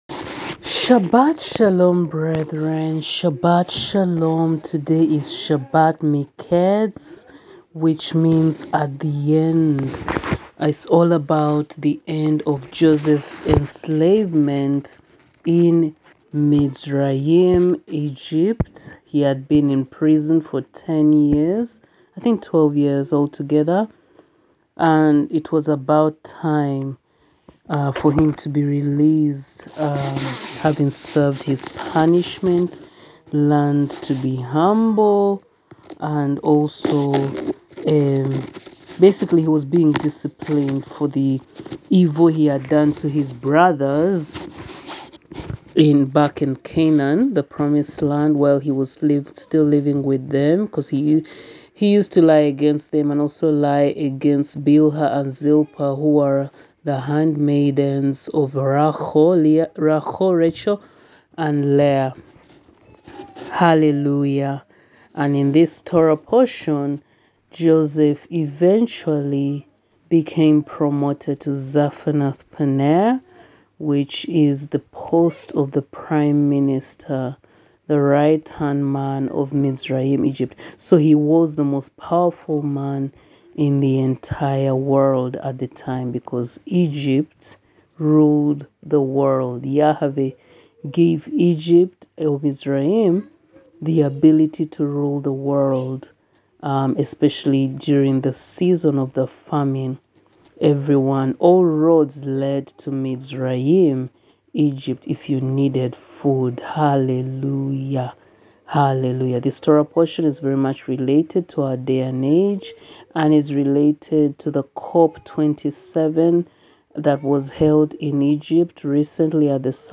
AUDIO Recording 427: SHABBAT MIKETZ/HANUKKAH Day 6: Songs 🎵 [➡1. Stand Up Stand Up For YAHUSHUA; ➡2. Fight The Good Fight With All Thy Might; ➡3. Maoz Tzur/Rock of Ages]; Joseph; John 10:22-41; etc… [Sat 24-12-22]